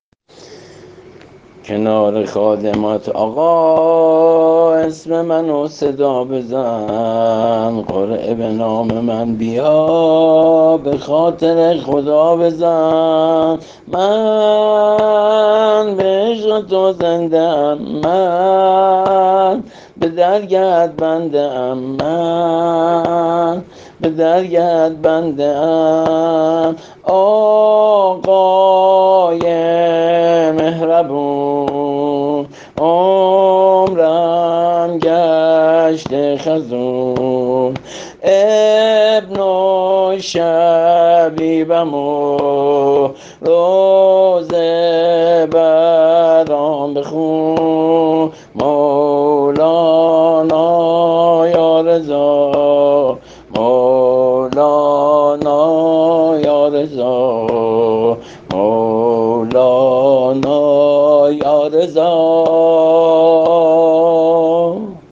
زمزمه‌وشور سینه‌زنی